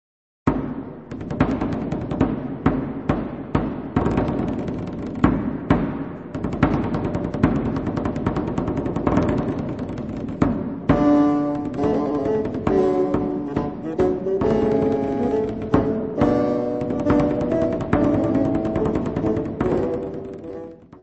fagote
cravo
tambor.
Music Category/Genre:  Classical Music
Pièces à deux basses en si mineur